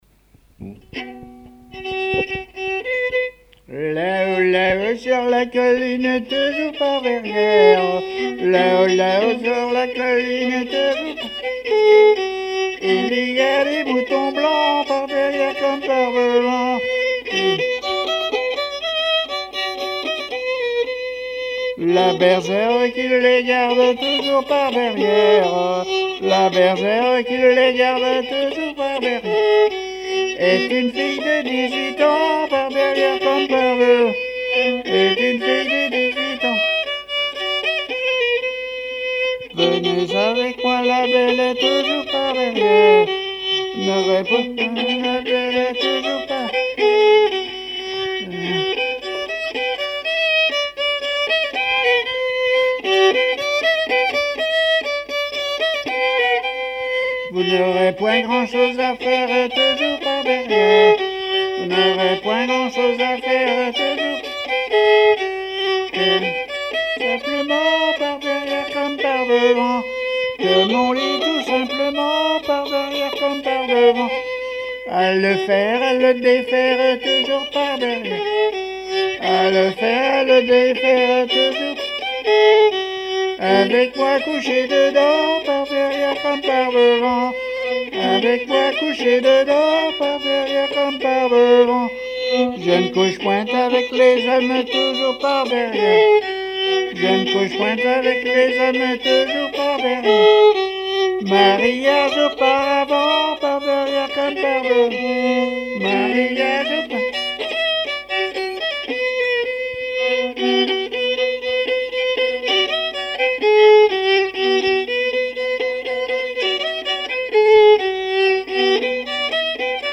Mémoires et Patrimoines vivants - RaddO est une base de données d'archives iconographiques et sonores.
Genre laisse
répertoire musical au violon
Pièce musicale inédite